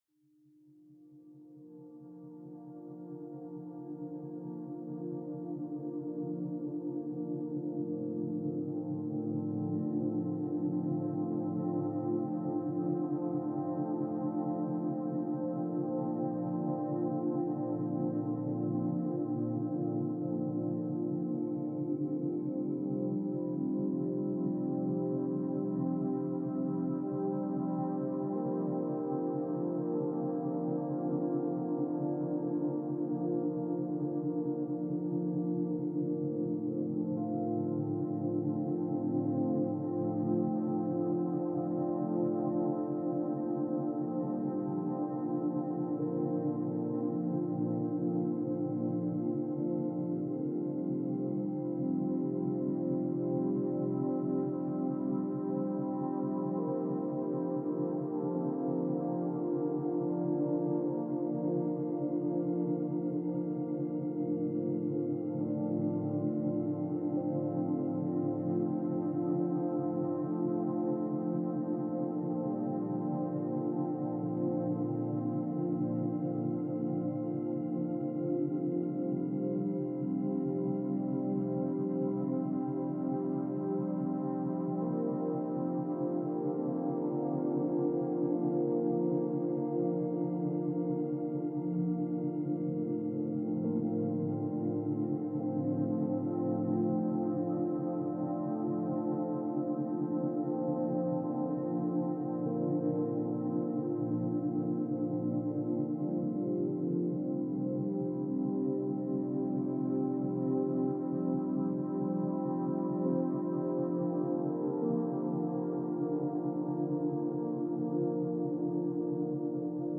Birdsong and Forest Ambience – Calm Reading and Study Session